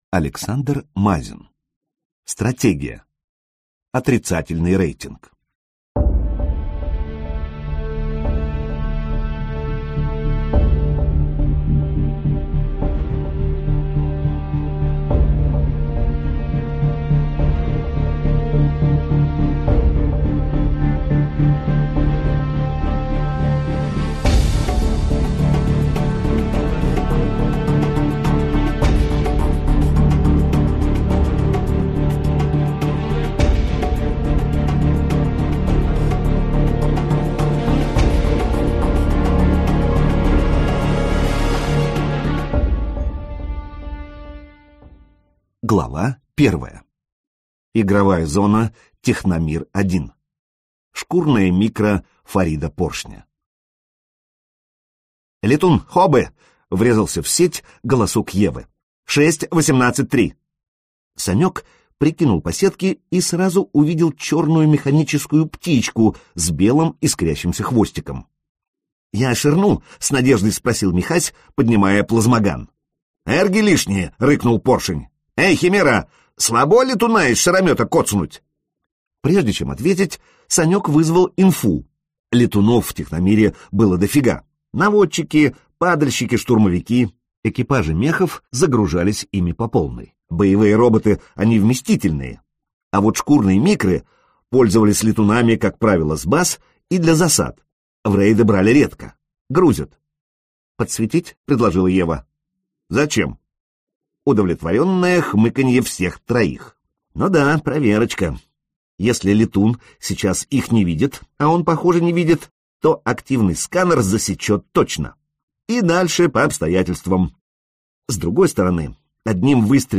Аудиокнига Отрицательный рейтинг - купить, скачать и слушать онлайн | КнигоПоиск